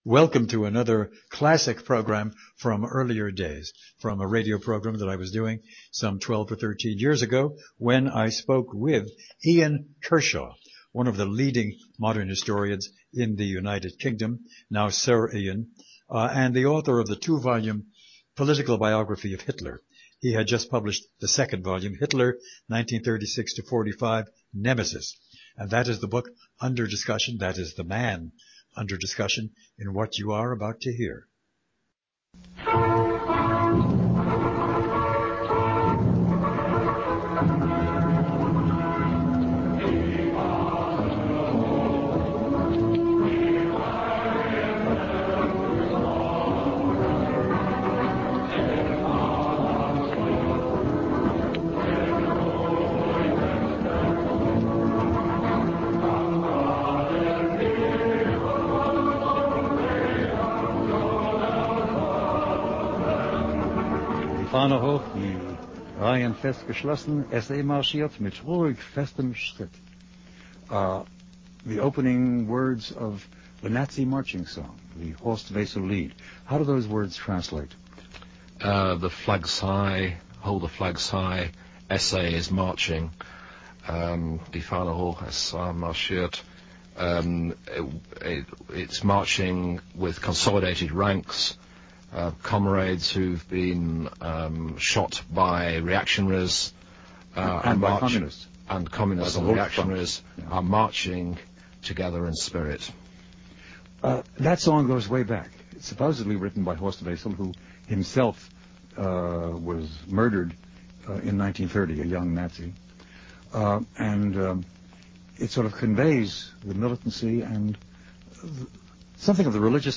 Here is the full interview that I did with him after the publication of the second volume of his great study of this freakish figure who was at the center of unaccountable, ultimate evil.